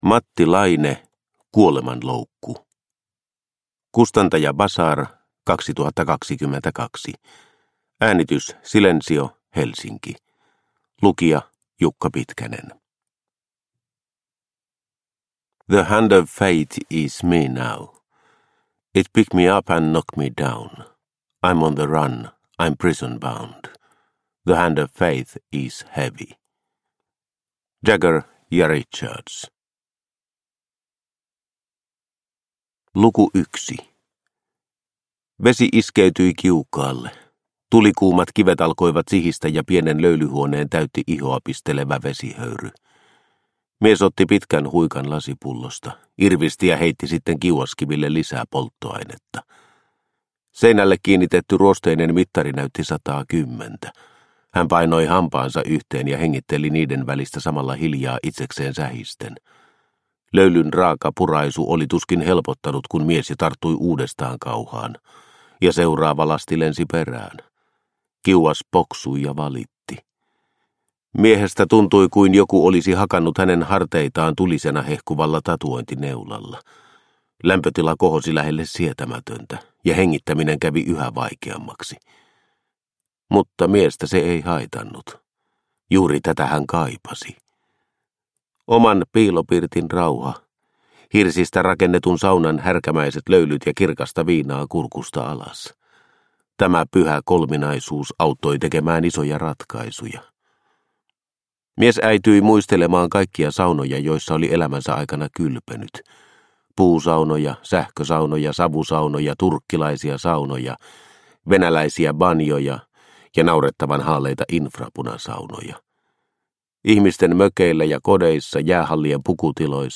Kuolemanloukku – Ljudbok – Laddas ner